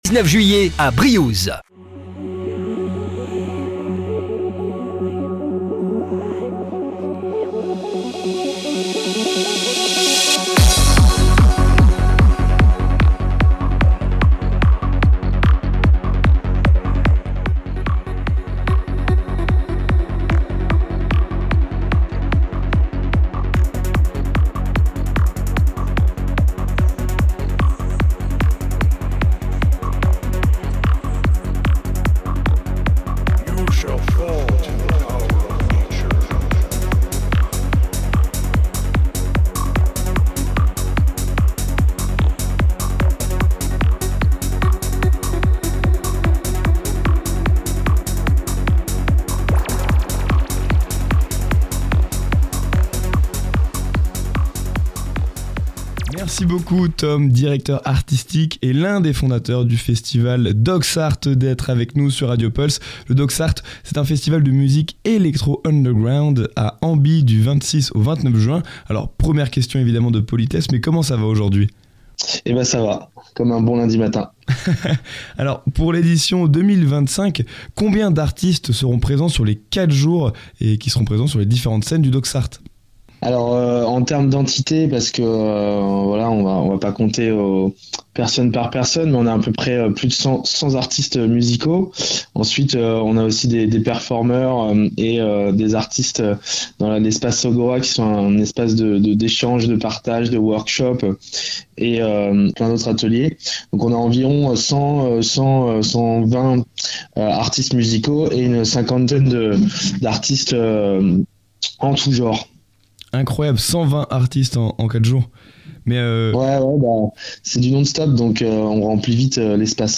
"C'est de la Locale" est une émission quotidienne diffusée en direct de 18 à 19h du lundi au vendredi. On y traite des infos associatives ou culturelles locales.